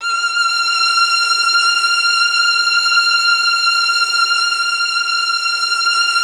MELLOTRON.19.wav